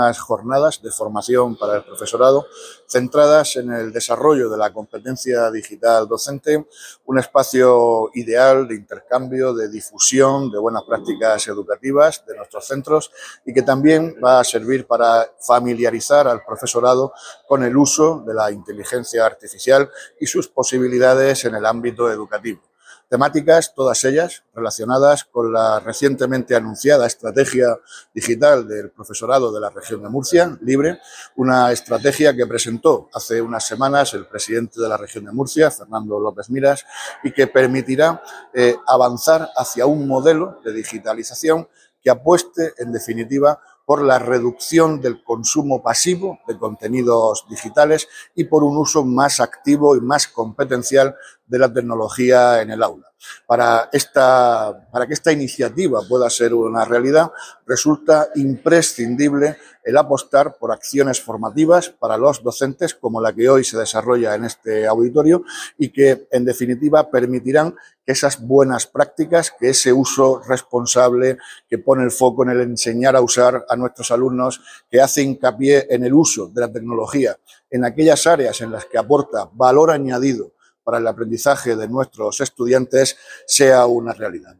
Contenidos Asociados: Declaraciones del consejero de Educación y Formación Profesional, Víctor Marín sobre la Estrategia de Digitalización Educativa de la Región de Murcia 'Libre'.